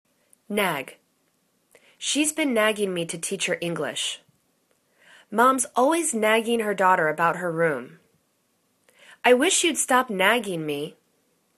nag     /nag/    v